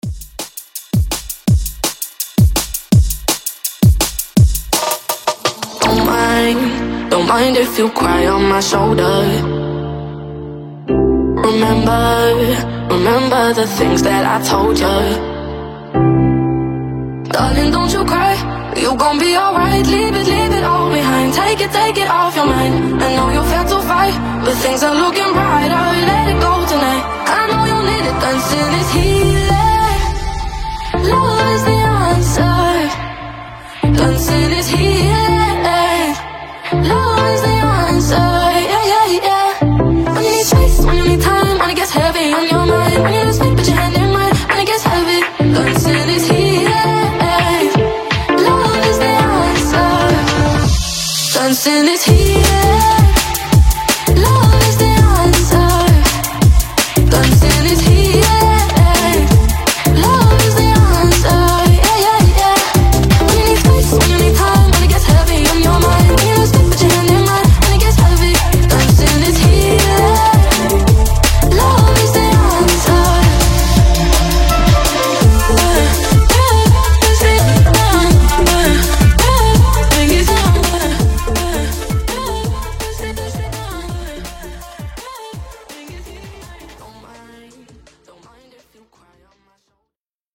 Genre: DANCE
Dirty BPM: 130 Time